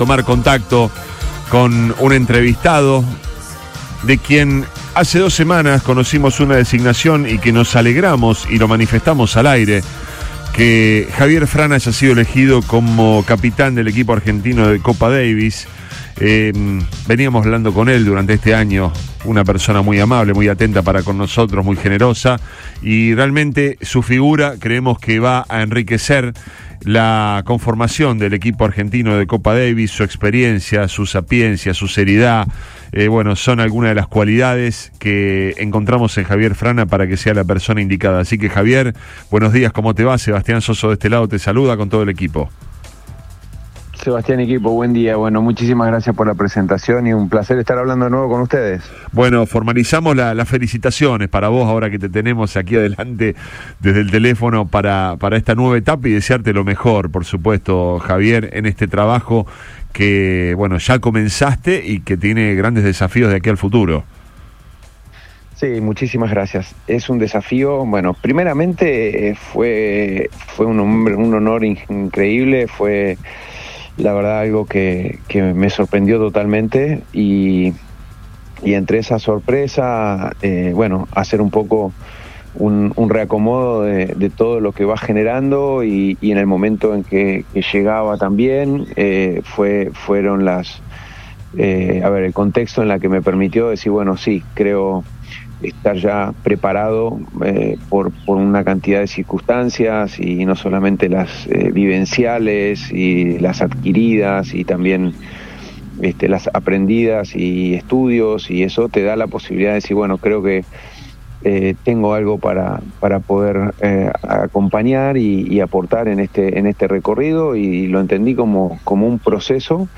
El flamante capitán del equipo argentino de la Copa Davis, Javier Frana dialogó con la mesa de Río Extra Primera Hora por FM Río 96.9 y analizó como tomó su designación al frente del equipo nacional de tenis.